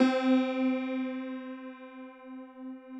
53r-pno10-C2.wav